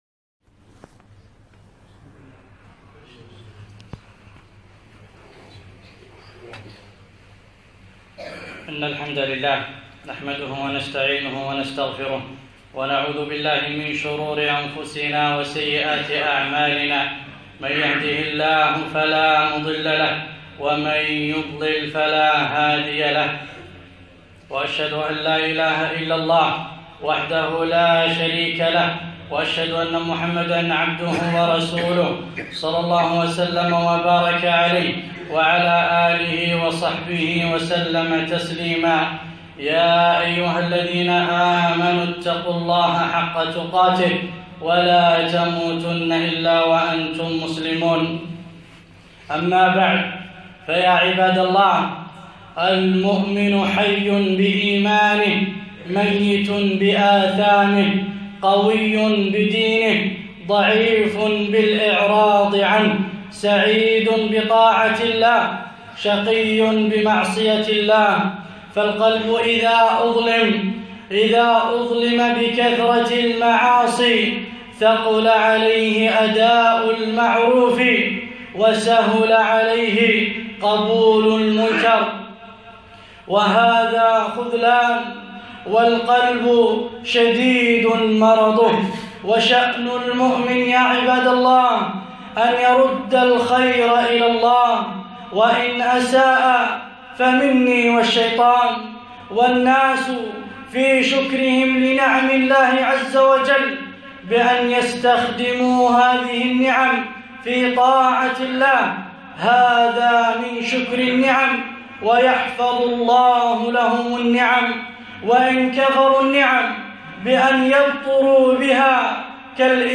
خطبة - المعاصي